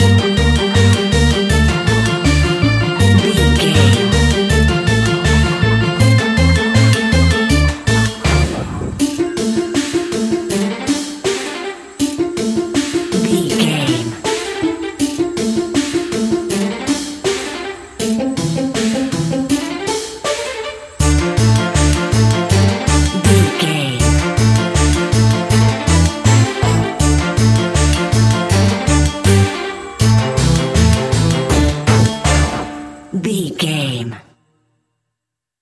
Aeolian/Minor
D
tension
ominous
dark
eerie
synthesiser
drum machine
creepy
spooky
mysterious
horror music
Horror Pads
Horror Synths